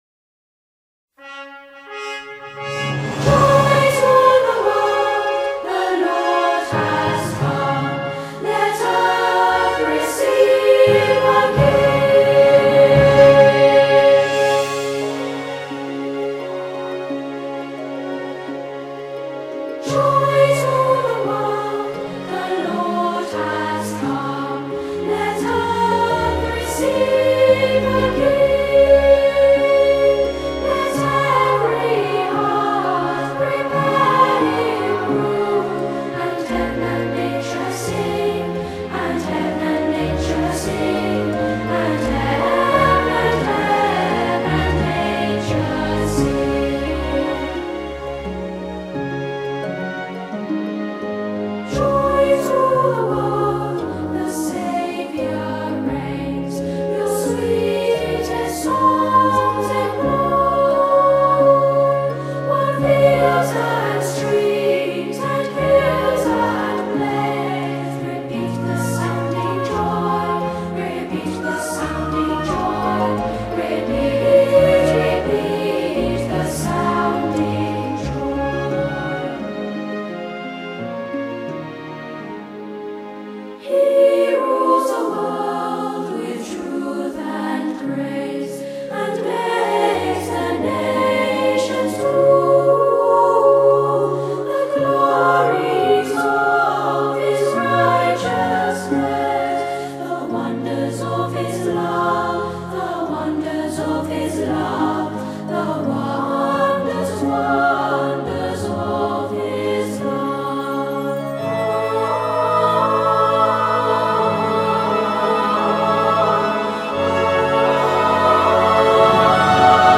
类　　别: Vocal, Christmas　　　　　　 　    　　.
这张专辑用澹澹的配器，只有一点点的弦乐团和钢琴伴奏，突显天使之翼